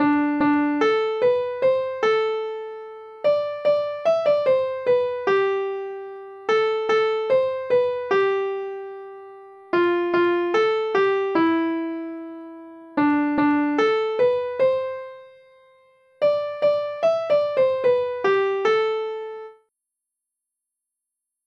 data-generert lydfil